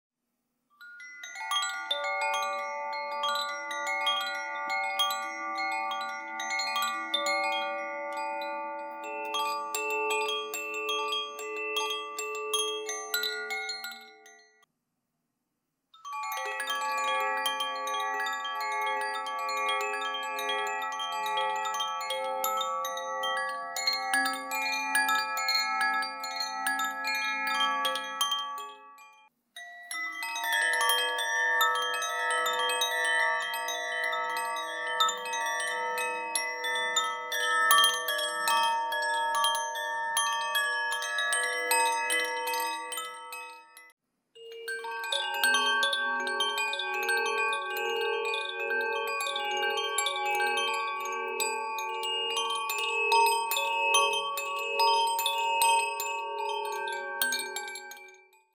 The Meinl Sonic Energy Cosmic Bamboo Chimes produce soft resonant tones that fill your space with serene sound.
Inside is a pendulum and a group of metal tines tuned to specific pitches. Easily modify the tone by switching between the two pendulums: acrylic (hard) and wood (soft).
This set contains four tunings matched to the time of day: Aurora (morning), Sol (day), Luna (evening), and Stella (night).